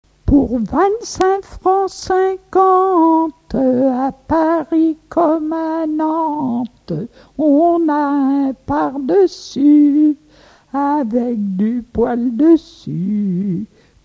Pièce musicale inédite